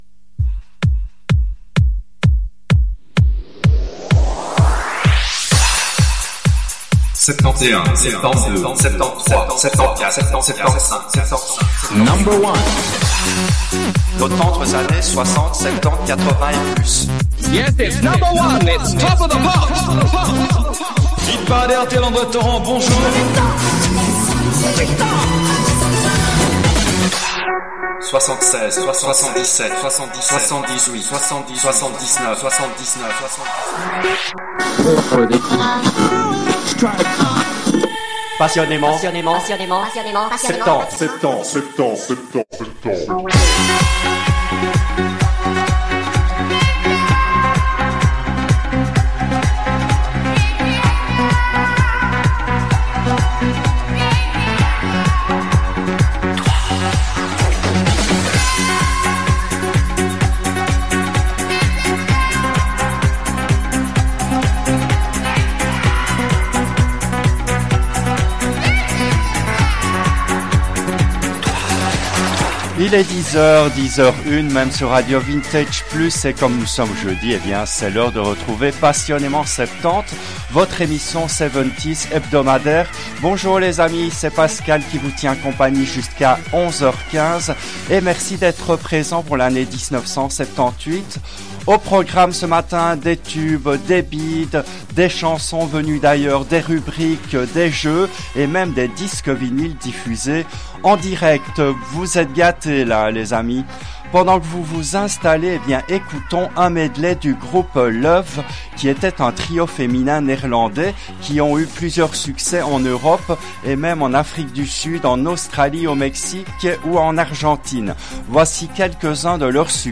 L’émission a été diffusée en direct le jeudi 15 mai 2025 à 10h depuis les studios belges de RADIO RV+.